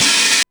Index of /musicradar/essential-drumkit-samples/DX:DMX Kit
DX Open Hat 01.wav